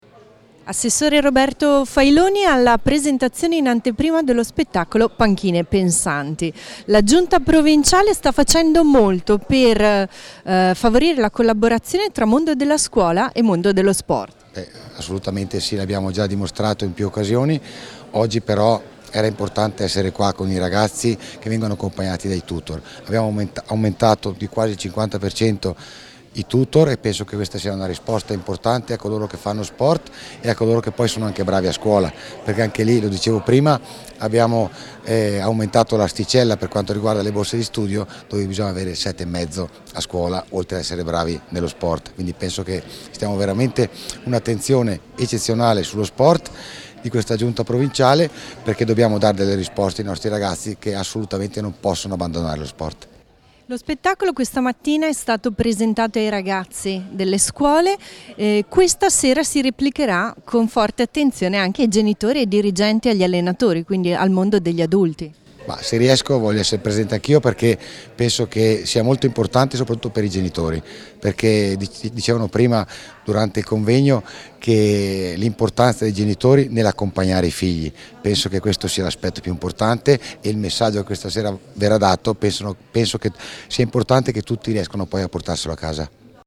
intervista_Ass._Roberto_Failoni_Panchine_Pensanti.mp3